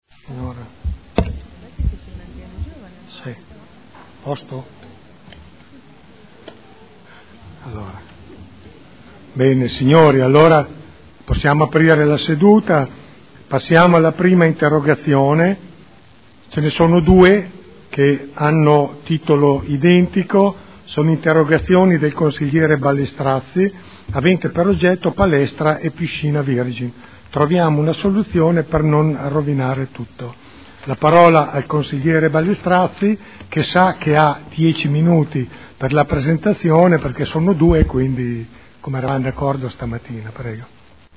Il Presidente Pellacani apre la seduta con le interrogazioni.